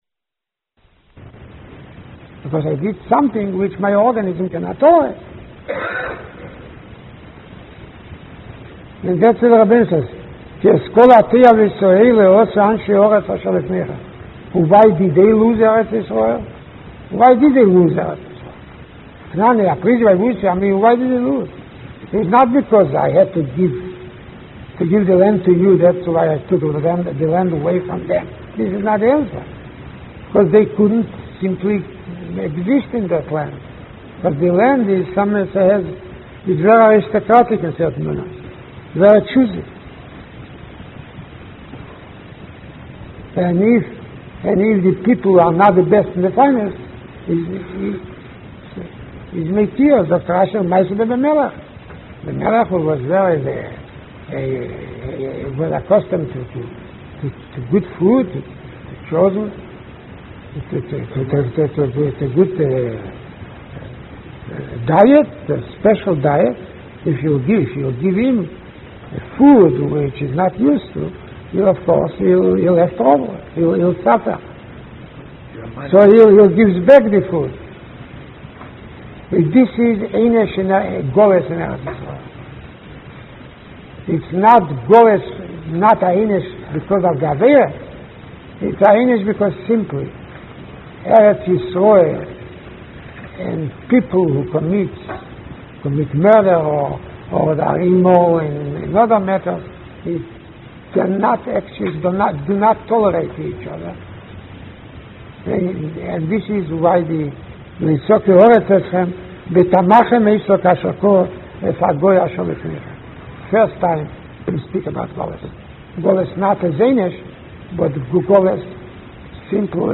Chumash Shiur YU 4/28/1981